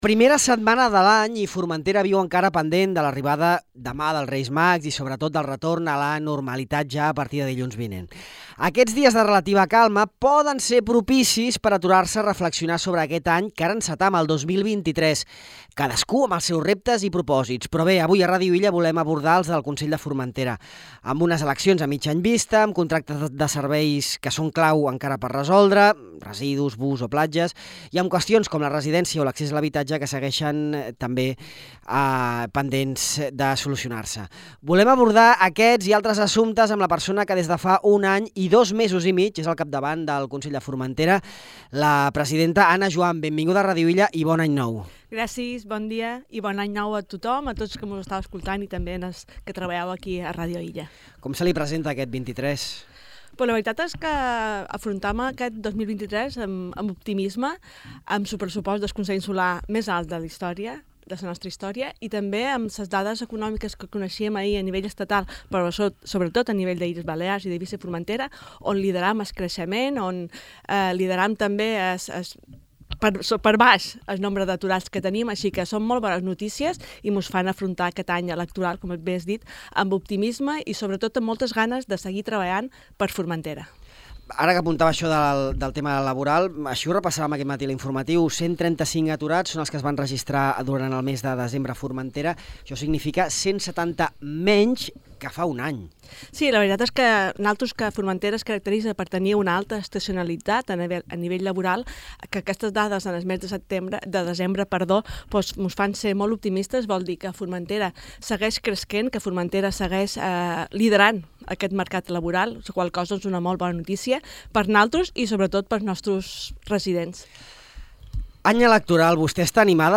L'oferta dels quioscos i altres serveis de platja per aquest estiu "no està en perill", segons ha afirmat la presidenta del Consell de Formentera, Ana Juan, en una entrevista en què ha repassat alguns dels reptes del nou any 2023, entre els quals, a més d'explicar la situació dels lots del litoral,